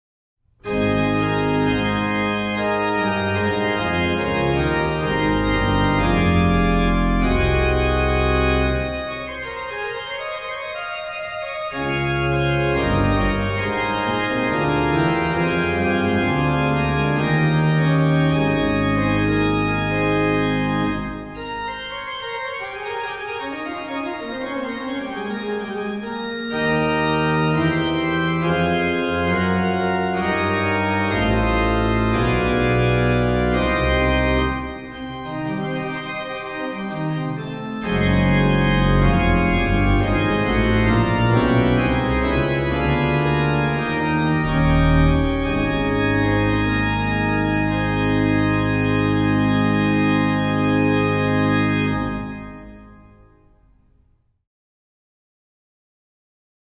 Venue   1721 Gottfried Silbermann organ, Georgenkirche, Rötha, Germany
Registration   HW: Pr8, Rfl8, Oct4, Oct2, Mix
Ped: Pr16, Pos16, HW/Ped